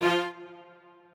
strings9_4.ogg